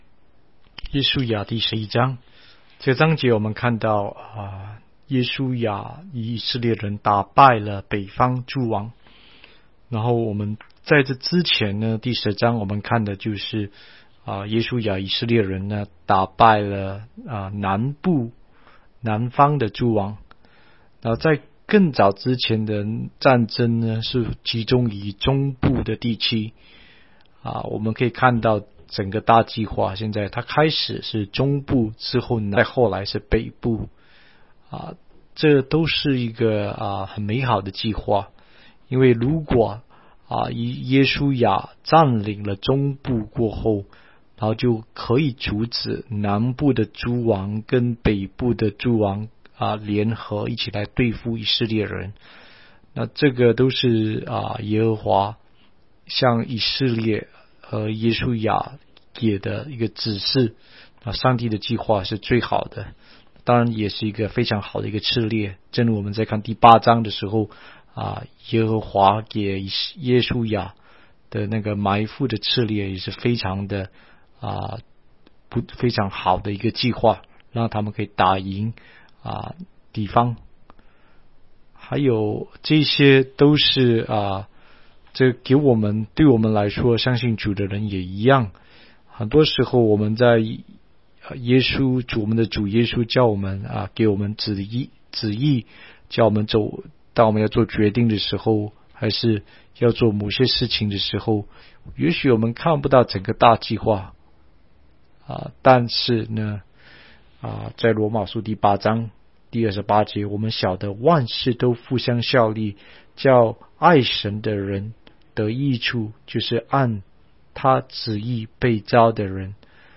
16街讲道录音 - 每日读经-《约书亚记》11章